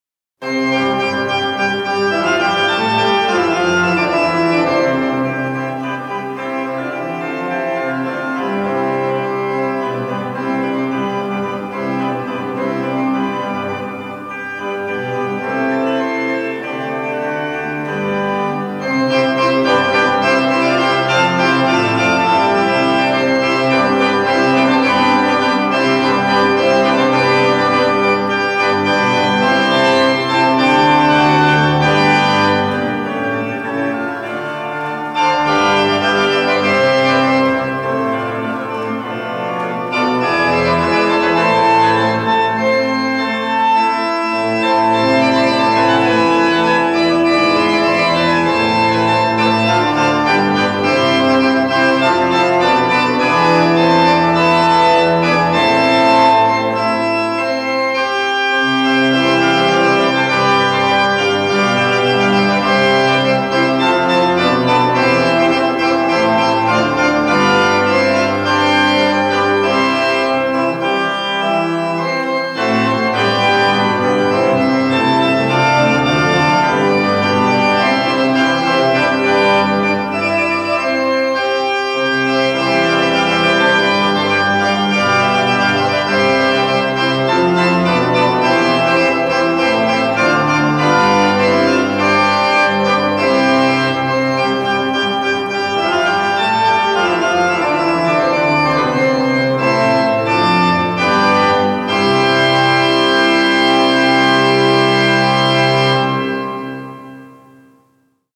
Wedding ceremony music
Organist